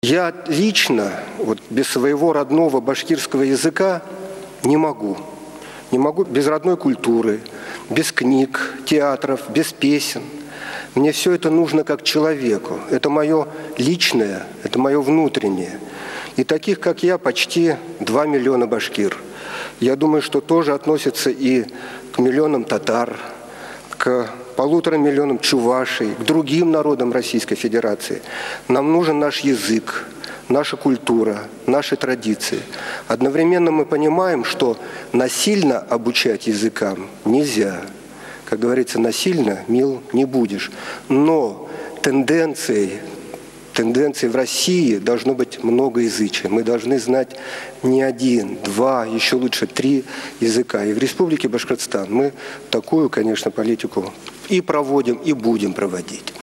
Уфада Русия Дәүләт шурасы утырышы үтте
Рәстәм Хәмитов чыгышыннан өзек